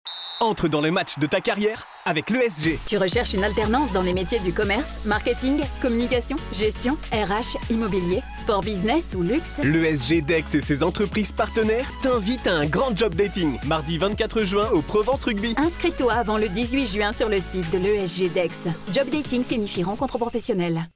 SPOTS AUDIO
campagne radio 13